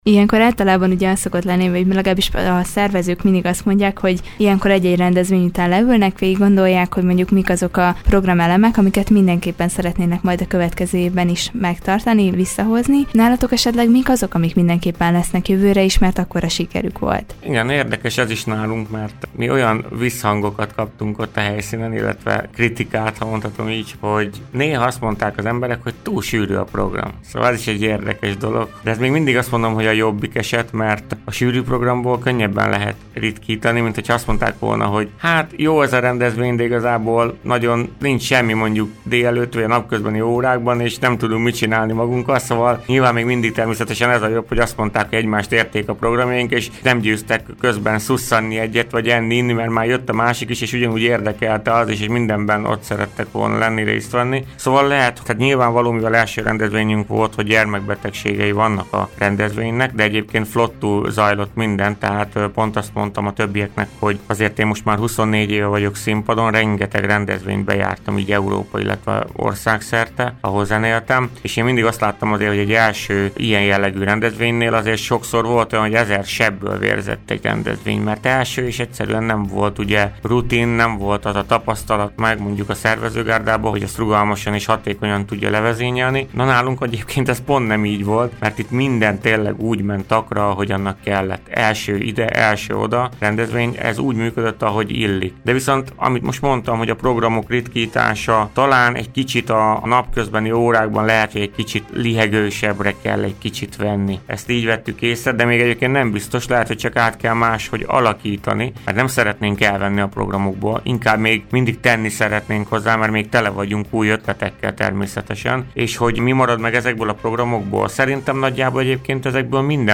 A hétvégén lezajlott az első Palmetta Tábor és Családi Napok. Ezzel kapcsolatban volt a Körös Hírcentrum stúdiójának vendége